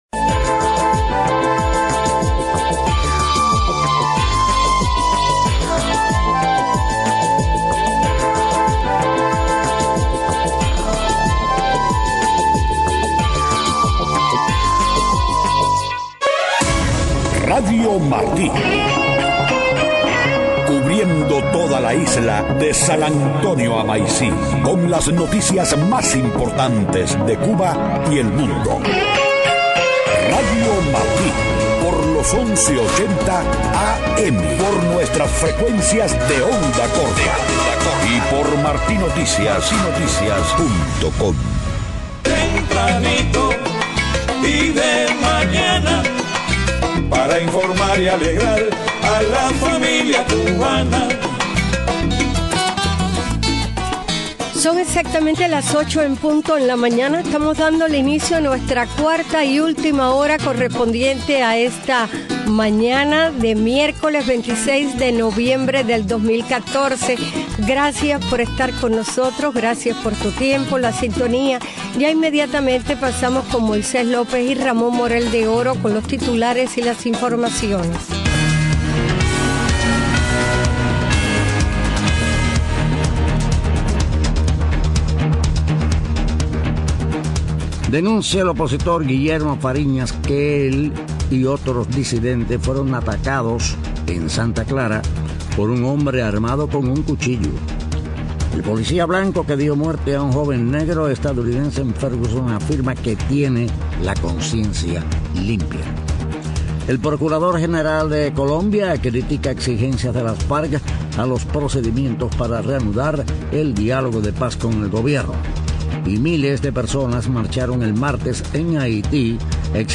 8:00 a.m. Noticias: Opositor Fariñas denunció que él y otros disidentes reunidos en su casa en Santa clara fueron atacados por un hombre armado con un cuchillo. Policía blanco que mató a joven negro en Ferguson, afirma que tiene la conciencia limpia.